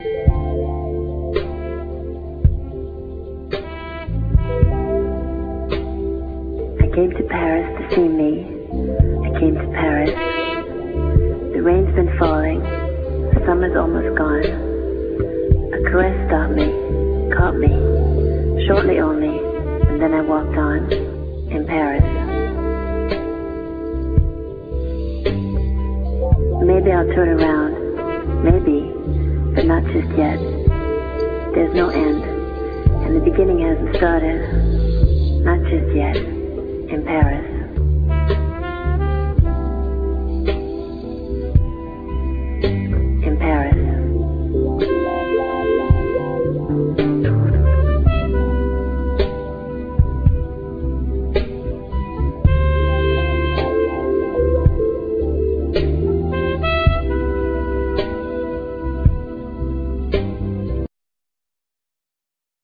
Trumpet
Turntables
Drums
Bass
Fender piano
Synthsizer, Hammond organ
African percussions
Guitar
Vocal, Violin